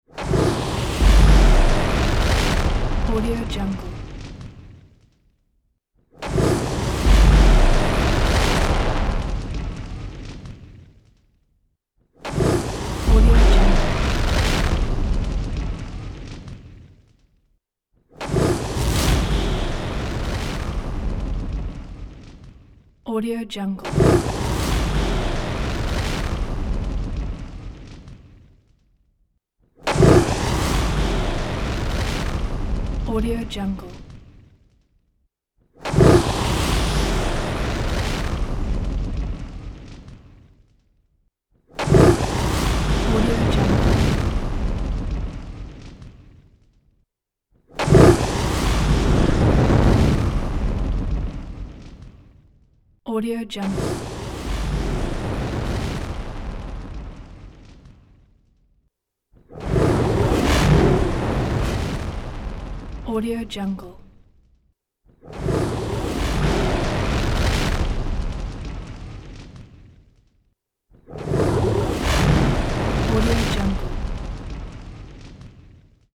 افکت صدای انفجار آتش
Sample rate 16-Bit Stereo, 44.1 kHz
Looped No